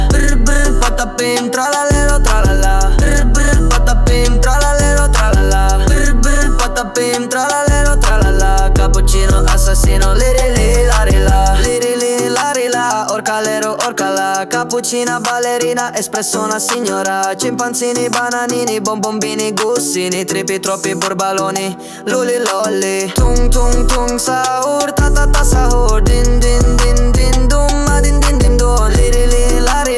Жанр: Хип-Хоп / Рэп